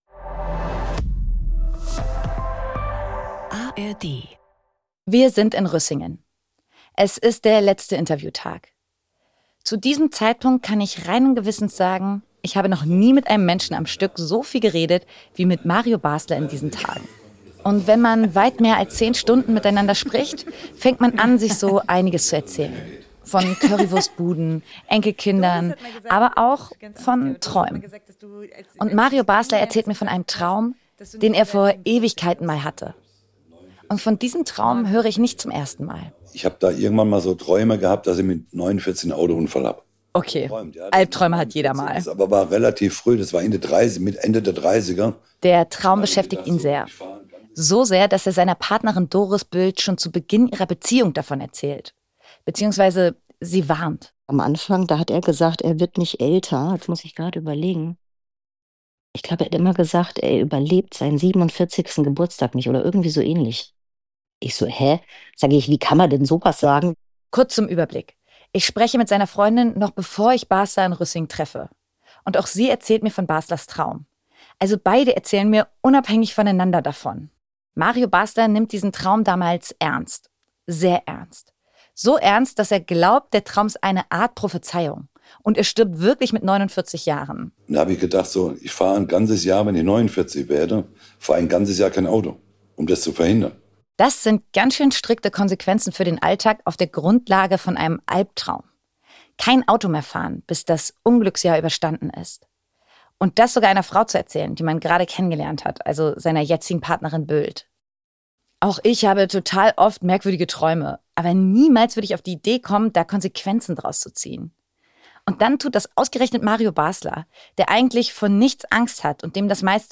Dennoch wird es laut zwischen den beiden.